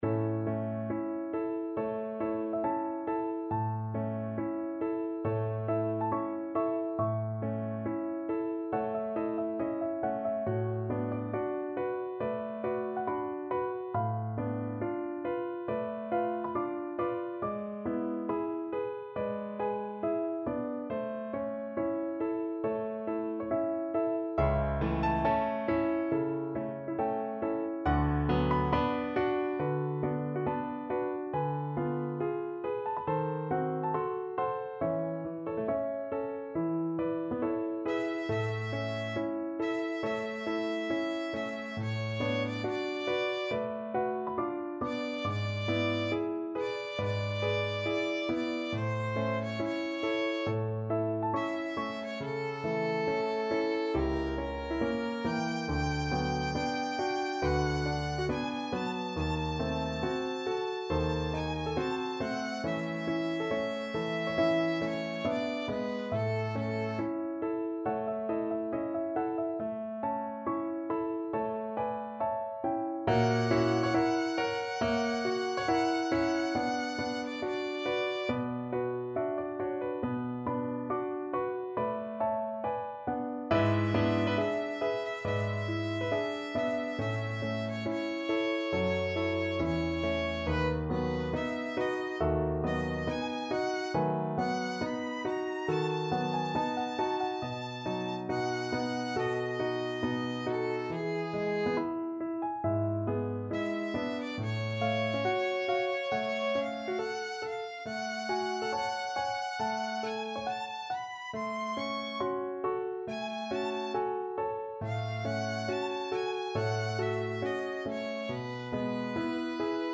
4/4 (View more 4/4 Music)
~ = 69 Adagio
Classical (View more Classical Violin Music)